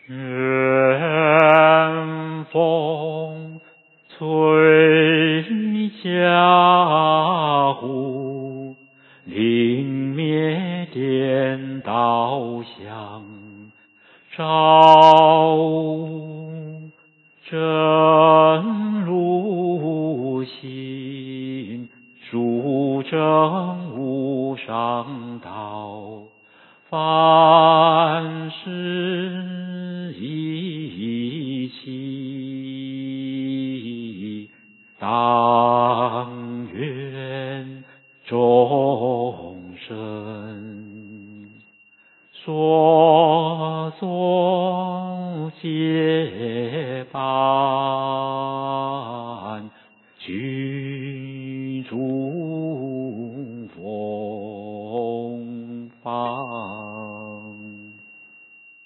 梵呗教学音档
二时临斋．结斋偈(有引磬)